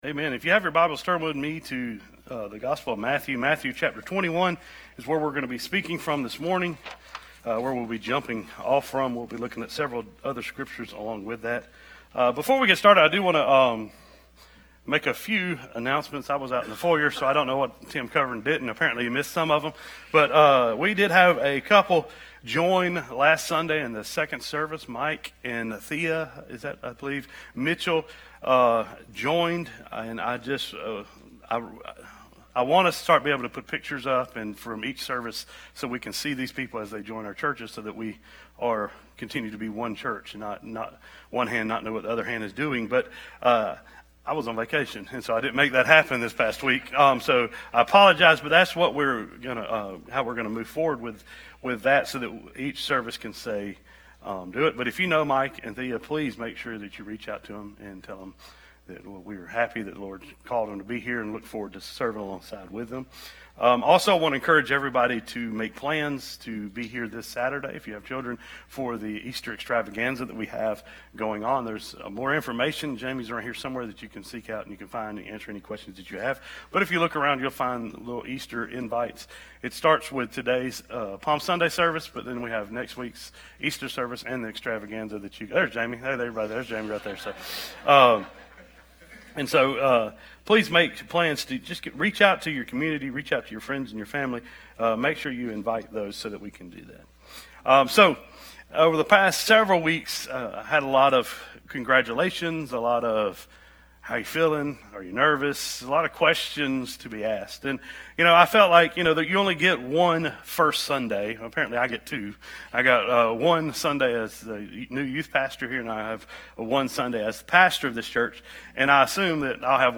Sermons | Piney Grove Baptist Church